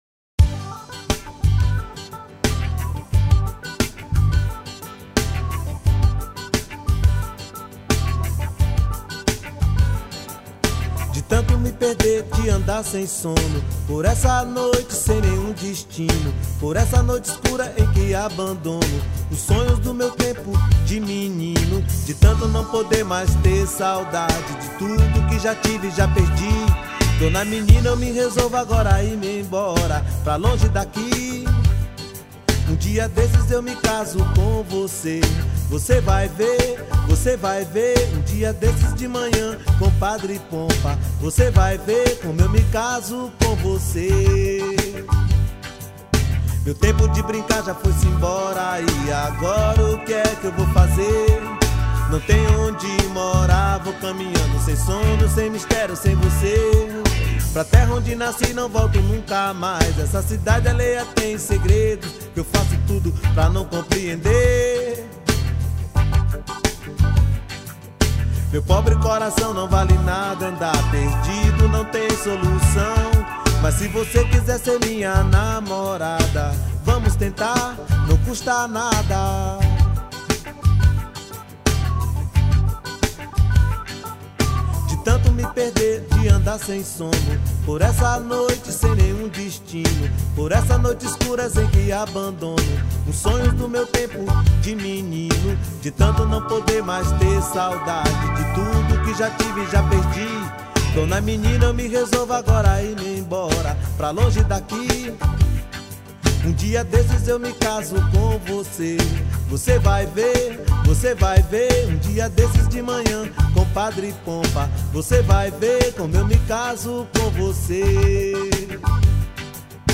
2620   03:23:00   Faixa: 11    Reggae